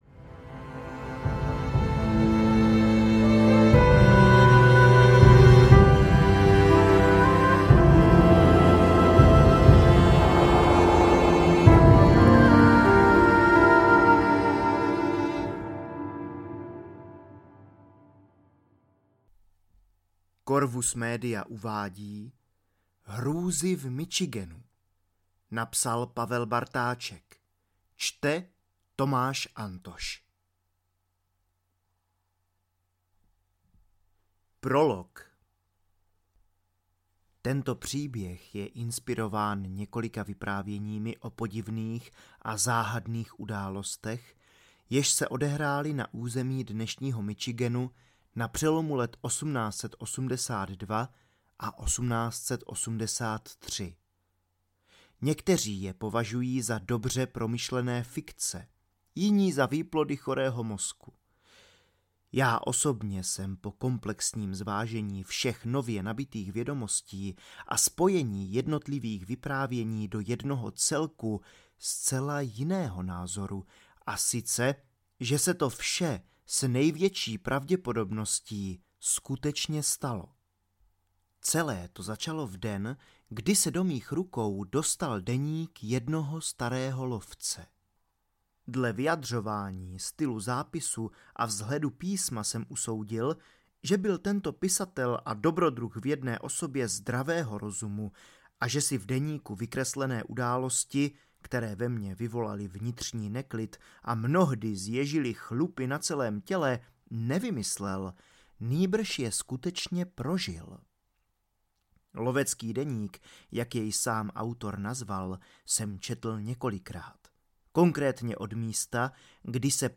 Hrůzy v Michiganu audiokniha
Ukázka z knihy